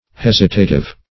Search Result for " hesitative" : The Collaborative International Dictionary of English v.0.48: Hesitative \Hes"i*ta*tive\, a. Showing, or characterized by, hesitation.